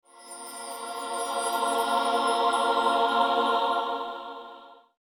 На этой странице собраны умиротворяющие звуки Бога и ангелов — небесные хоры, божественные мелодии и атмосферные треки для медитации, релаксации или творческих проектов.
Небесное пение ангела при его явлении